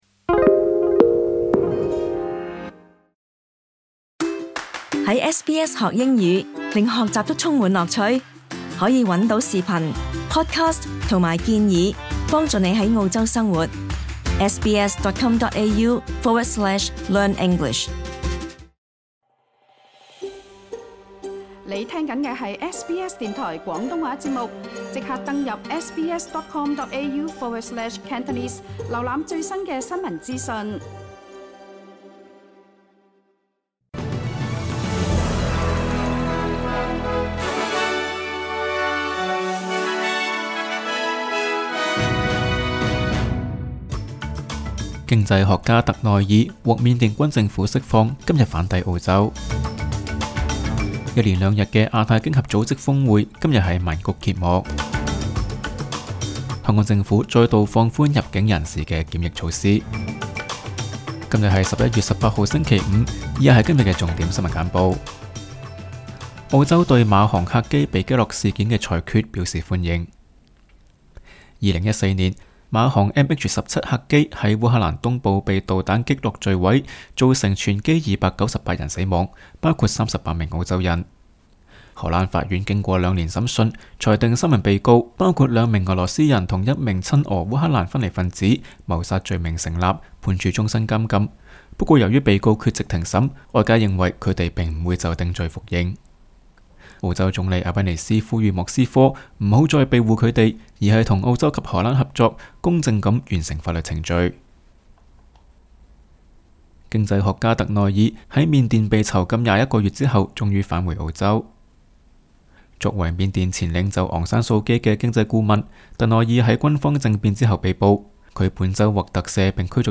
SBS 廣東話節目新聞簡報 Source: SBS / SBS Cantonese